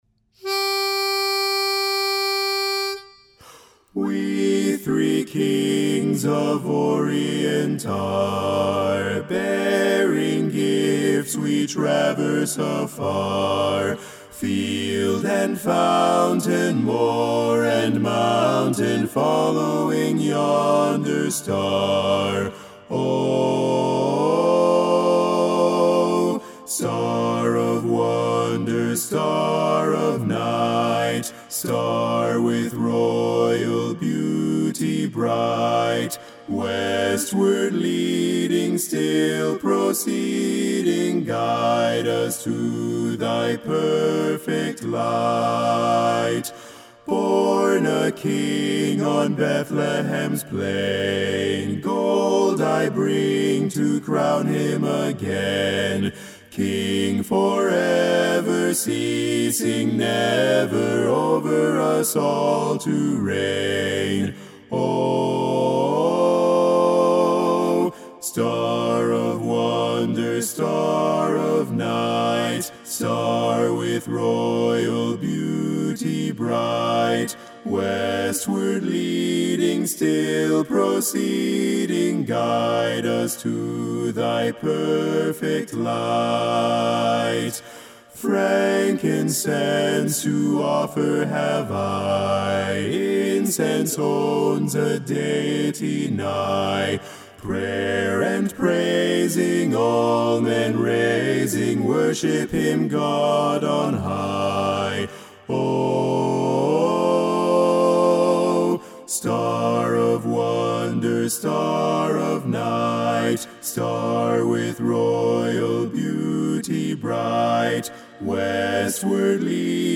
BASS - We Three Kings.mp3